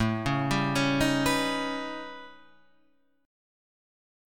A 9th Flat 5th